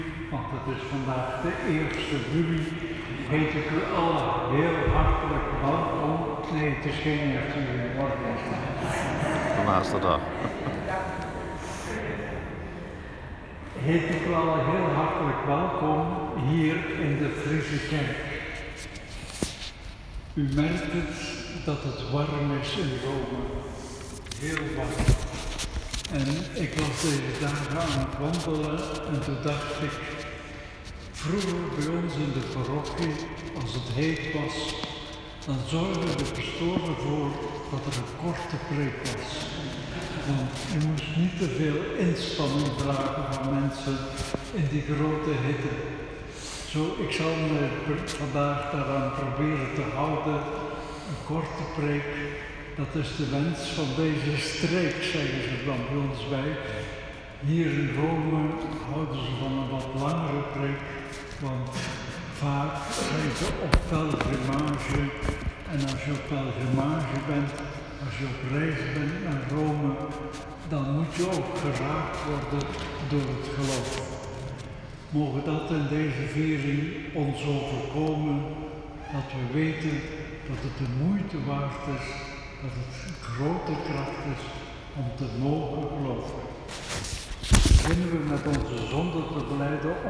Liturgie; Celebranten, vrijwilligers, gasten; Lezingen, Evangelie; Voorbeden 30 juni 2019; Inleidend woord, preek en gebed door Mgr. Hurkmans; Foto verslag
Inleiding.m4a